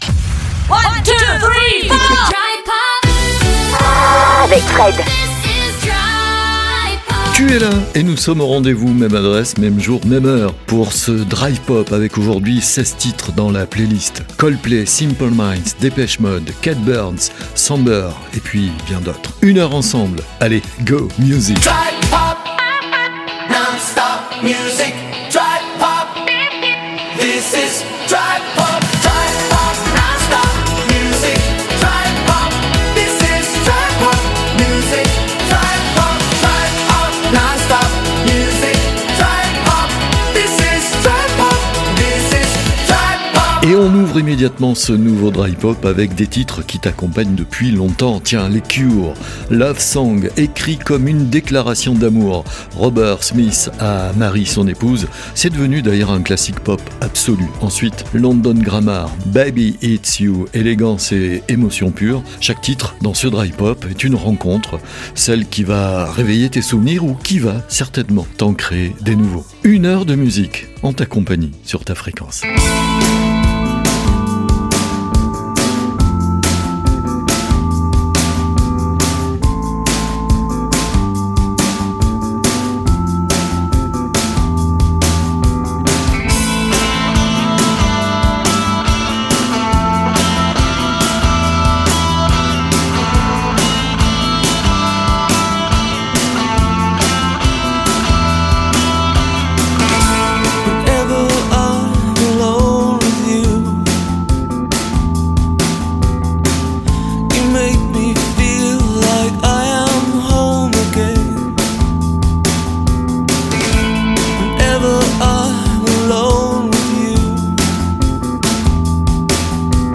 Une playlist de 16 titres.